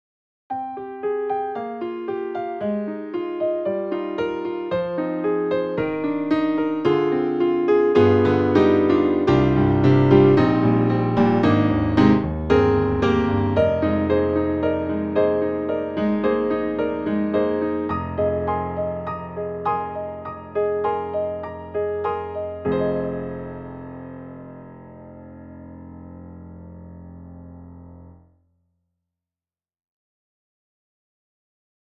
特集：徹底比較！DTM音源ピアノ音色聴き比べ - S-studio2
Concert
e-instruments_Session Keys Grand S_Concert.mp3